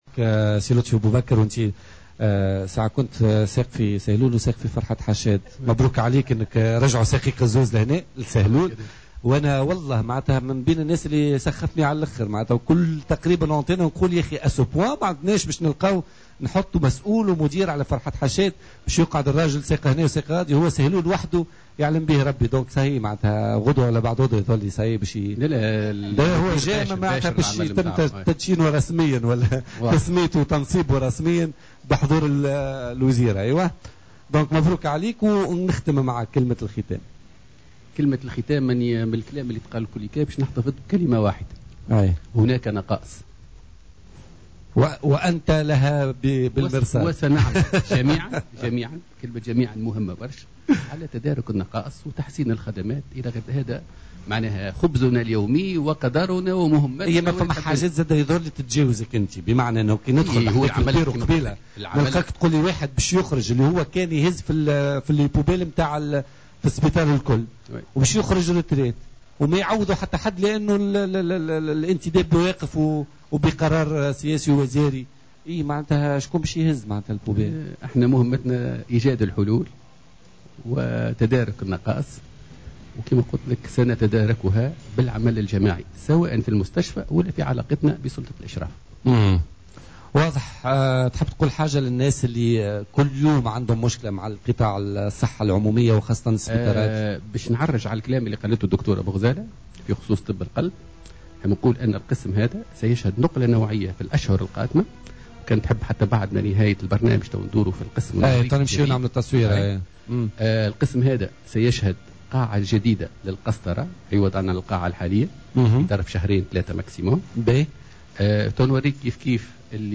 بث اليوم مباشرة من المستشفى